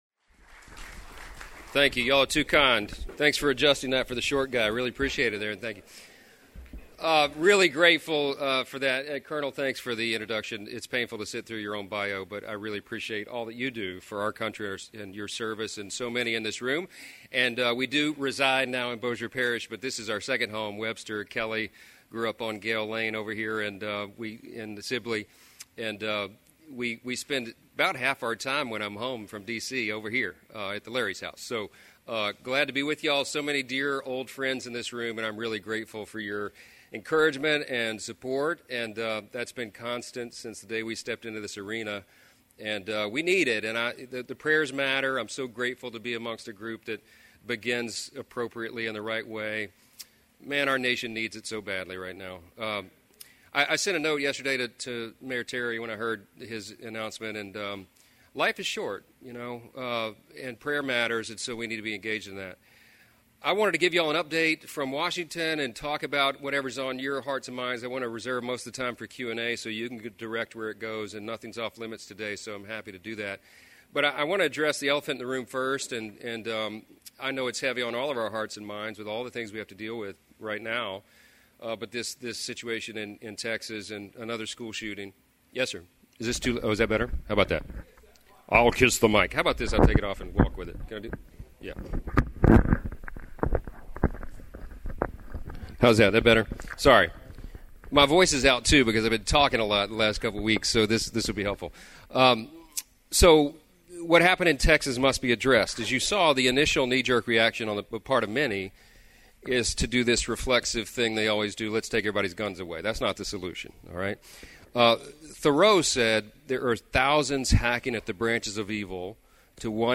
The Minden Lions Club heard from Congressman Mike Johnson at their May 26th meeting.